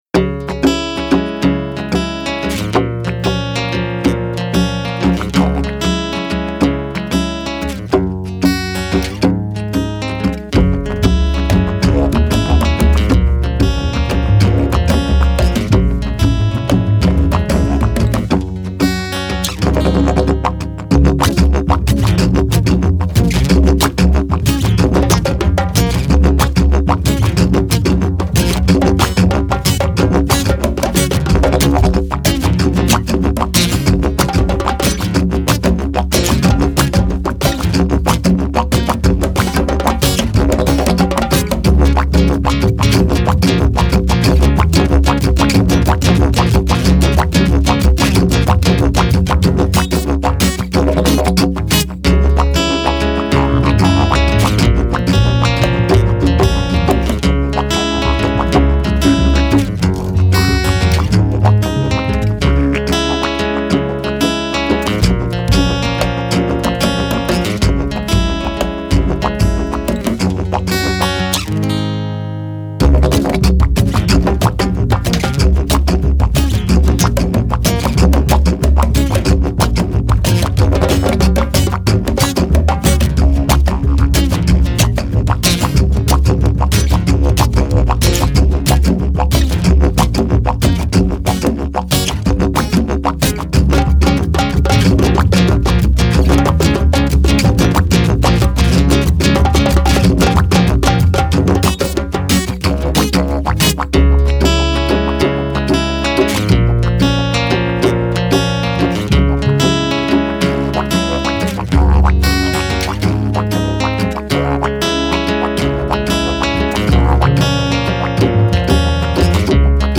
Sitar
Genre: World.